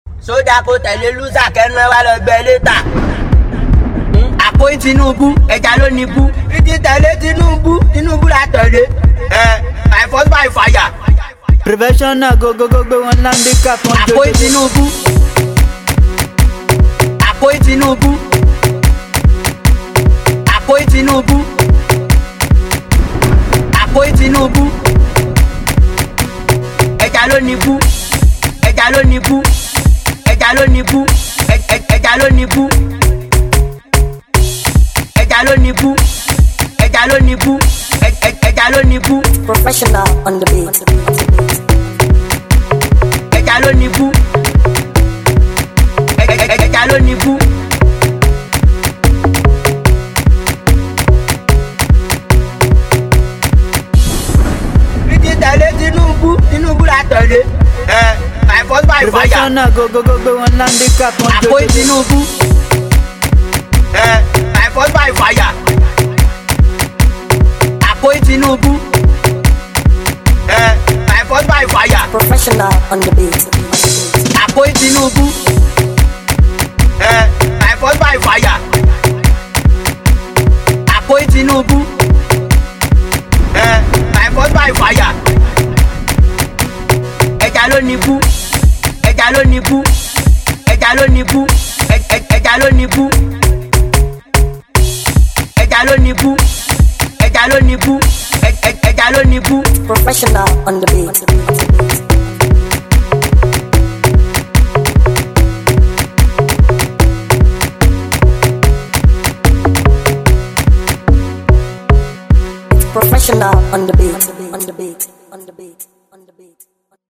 Super talented Nigerian singer
sharp sweet new tune